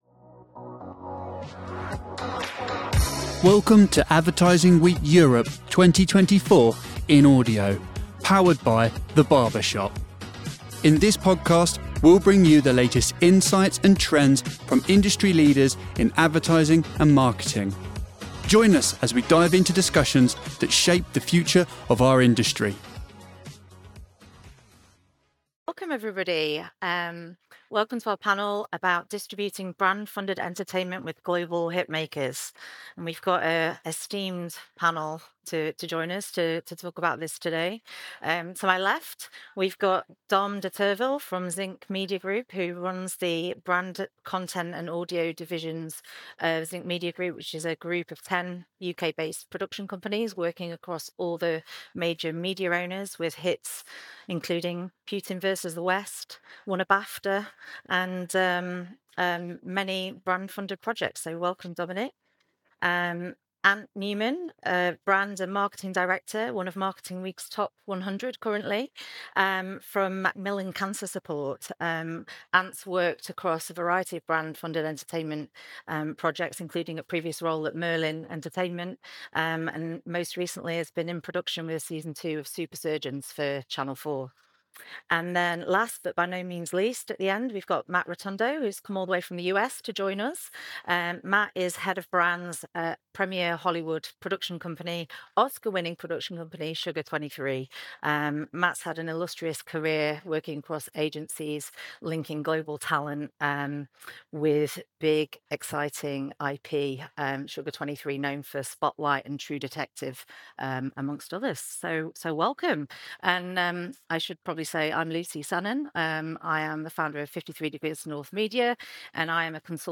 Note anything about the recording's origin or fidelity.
Distributing Brand Funded Entertainment with Global Hit Makers - Advertising Week Europe 2024 in Audio